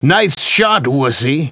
These Deerhunter Wavs Are From A Hunting CD Game Where Deer Hunt People That I Had Years Ago